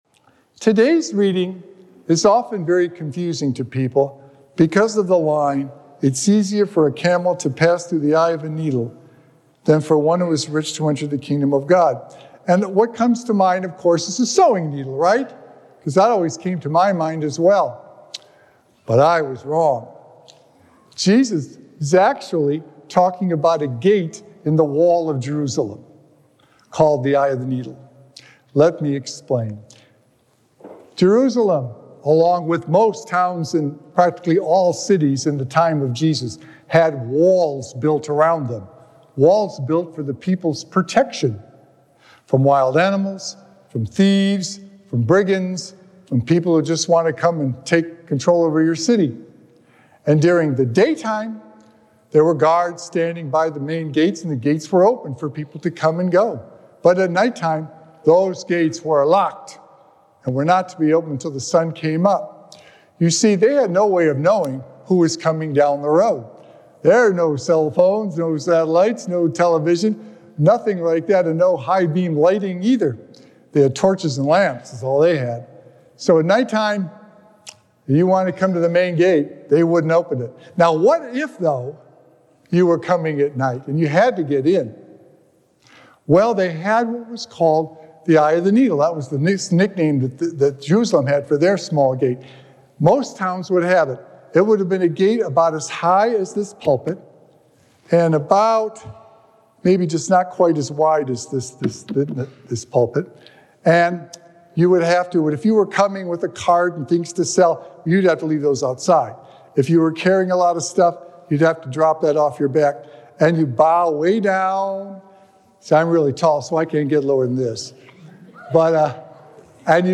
Sacred Echoes - Weekly Homilies Revealed Did You Care?